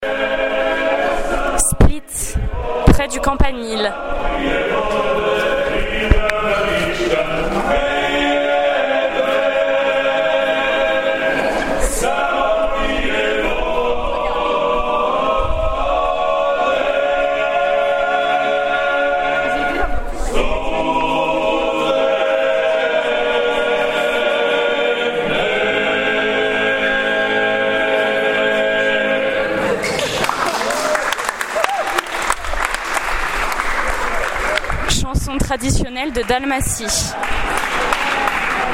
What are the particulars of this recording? Split - Croatie